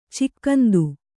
♪ cikkandu